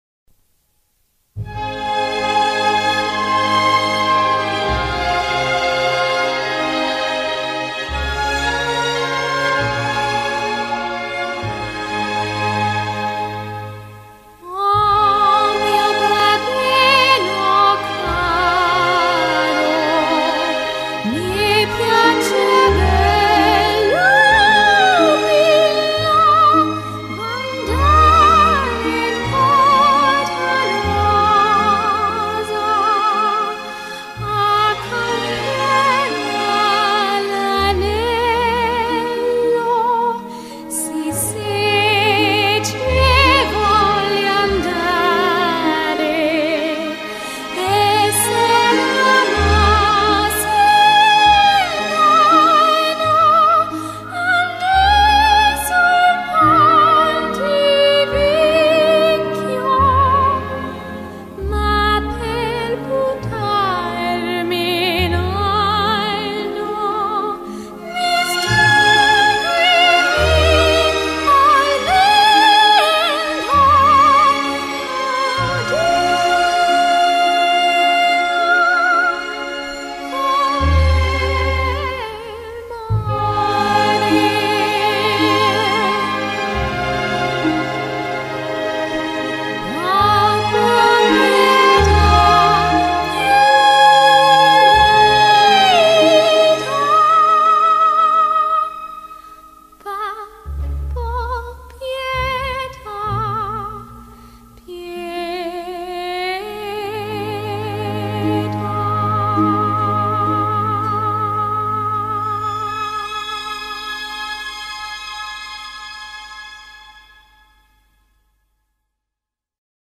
Soprano - Female Classical Singer